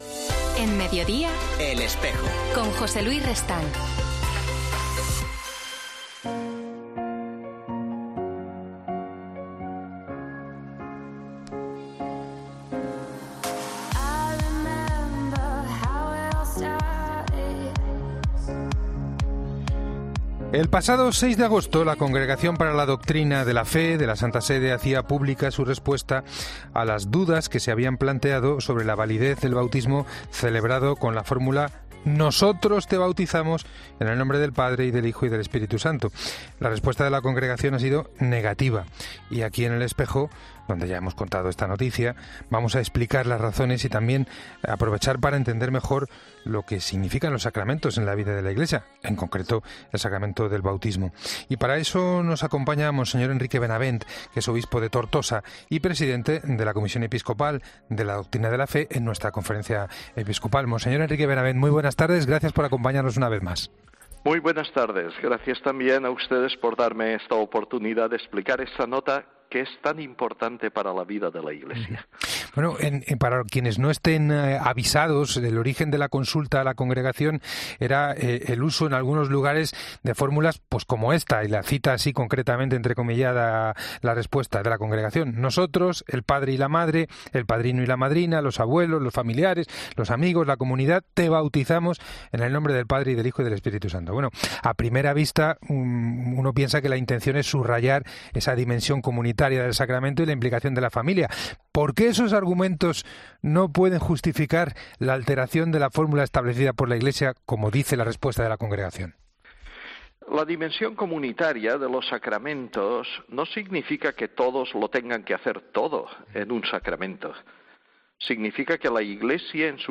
En 'El Espejo' de la Cadena COPE, monseñor Enrique Benavent, obispo de Tortosa y presidente de la Comisión Episcopal de Doctrina de la Fe de la Conferencia Episcopal Española ha explicado las razones de esta decisión.